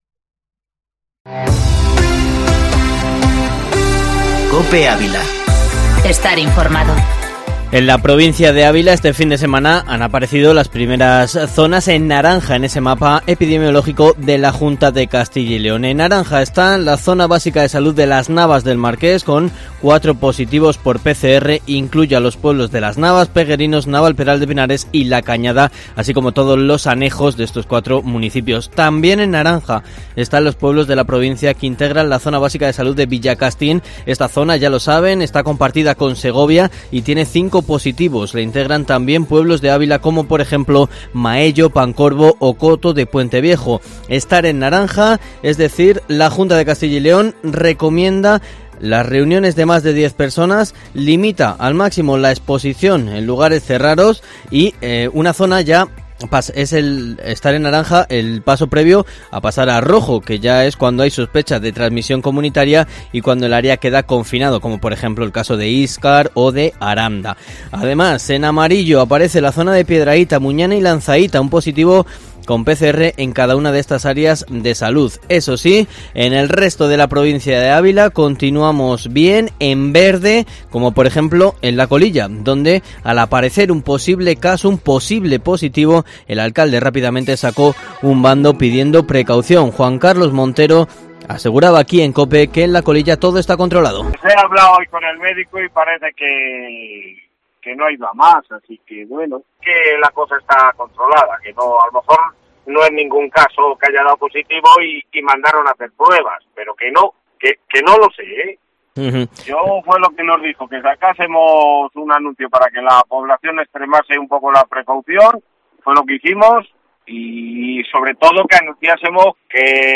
Informativo matinal Herrera en COPE Ávila 10/08/2020